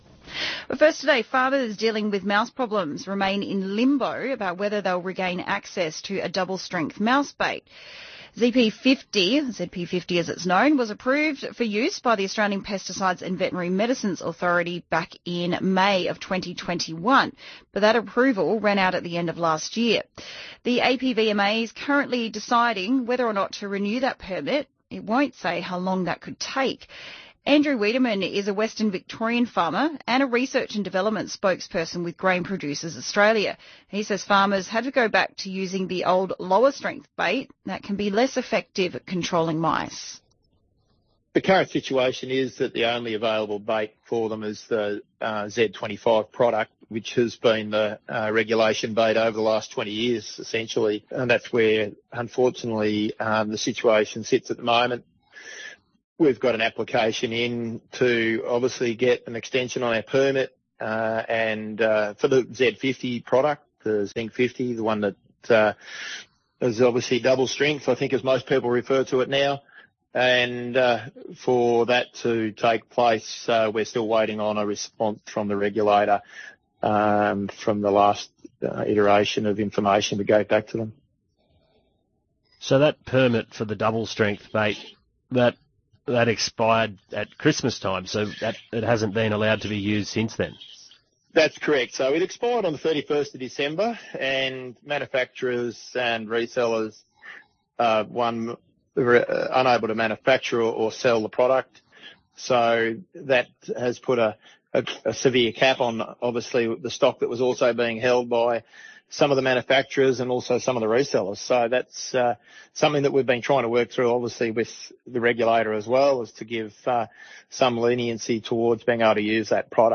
GPA’s work to support Australian grain producers with being able to access new technologies and tools to help control mice and protect their crops from these profit-eating pests was discussed on ABC Country Hour this week.